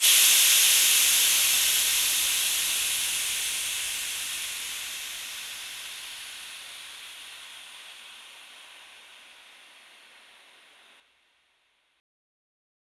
SteamReleaseValve.wav